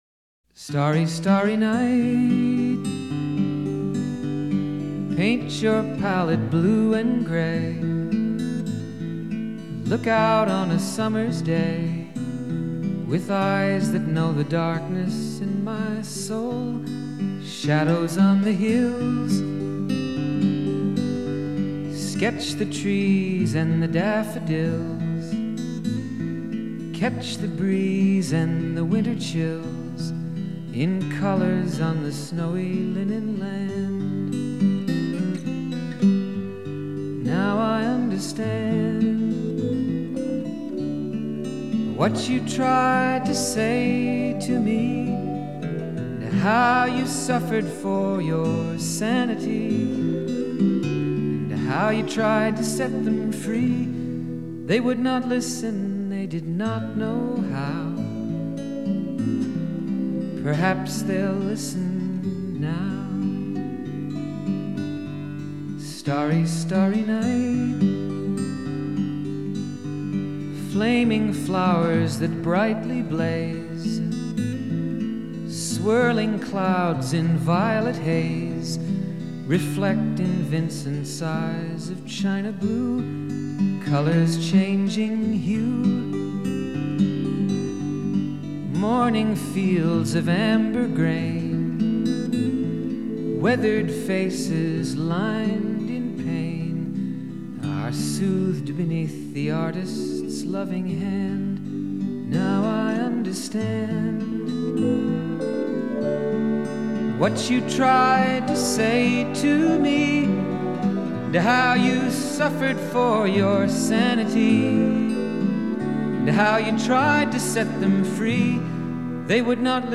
That song is achingly poignant.